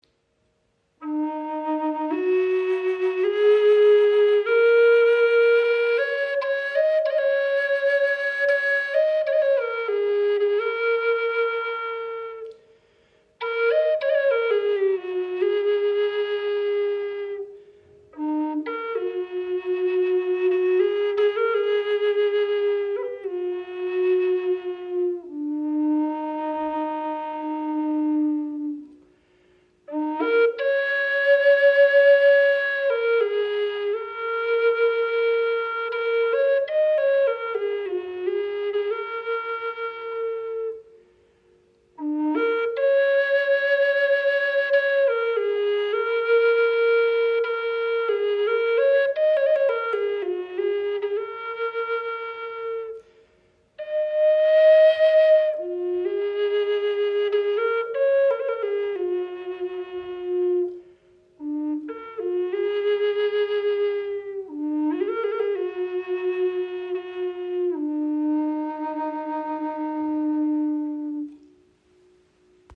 • Icon Gesamtlänge 67,5  cm, 24 mm Innenbohrung – warmer, klarer Klang
Diese handgefertigte Gebetsflöte aus Paduk-Holz verbindet kraftvolle Tiefe mit einer warmen, resonanten Klangstimme. Das rötlich schimmernde Hartholz verleiht der Flöte eine lebendige Präsenz und einen klaren, tragenden Ton, der sich weit im Raum entfaltet und zugleich weich und erdig wirkt.
Die Stimmung E♭-Moll schenkt der Flöte eine besonders meditative Klangfarbe. Jeder Ton entfaltet sich langsam, singt leicht windig und lädt dazu ein, Musik als Ausdruck des Atems zu erleben.
Präzise Intonation, sanftes Ansprechverhalten mit leicht windiger Stimme und ihr runder Ton machen sie zu einer verlässlichen Begleiterin für freies Spiel, Meditation und Klangarbeit.